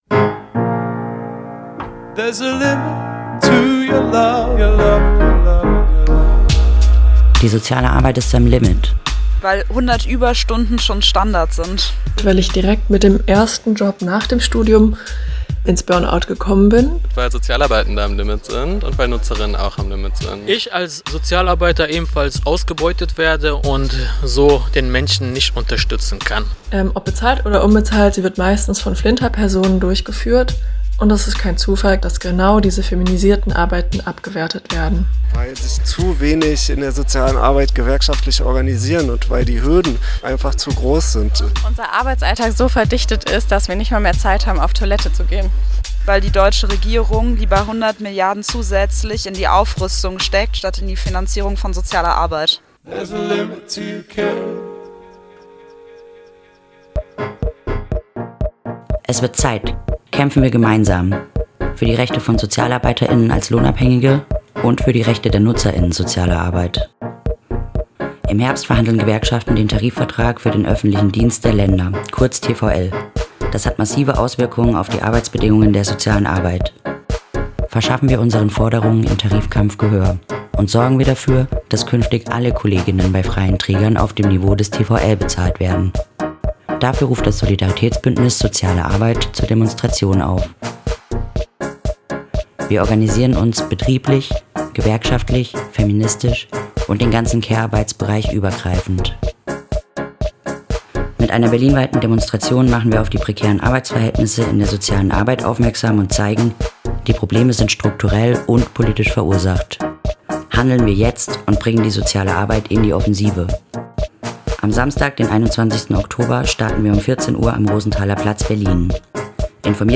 Jingle zur Demo.
2023_Jingle_Solibuendnis_Soziale_Arbeit.mp3